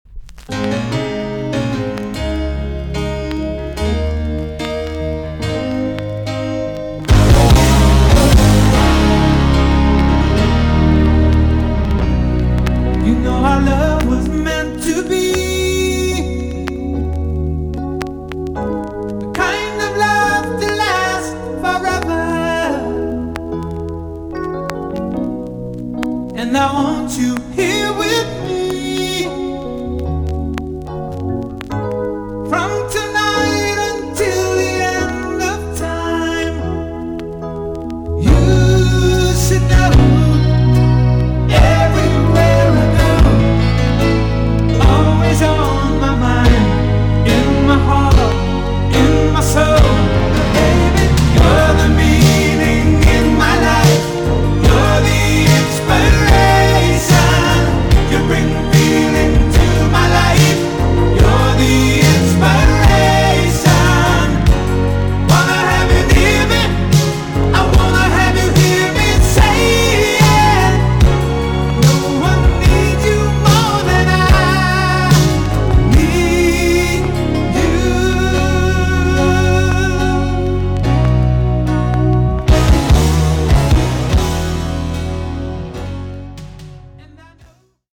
VG+~EX- 音はキレイです。